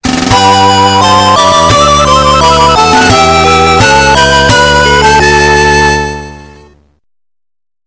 クラシックの名曲をアレンジしたボーナスサウンド！